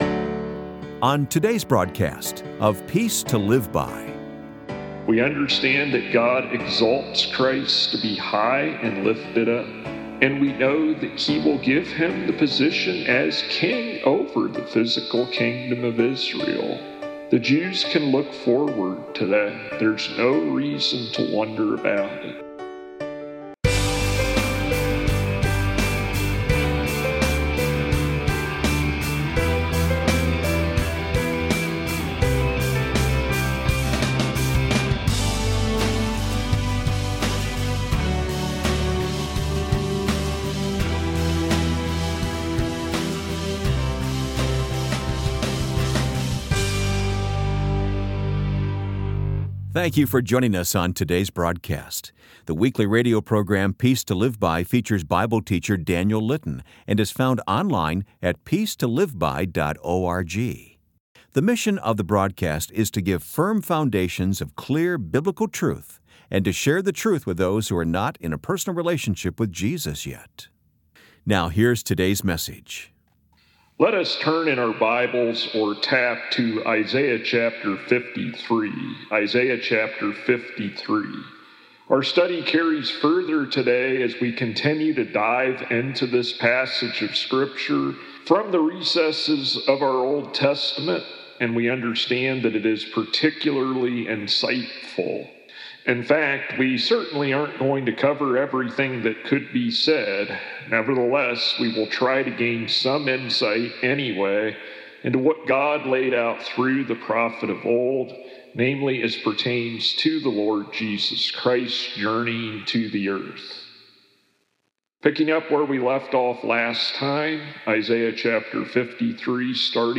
Episode 32 Full Sermon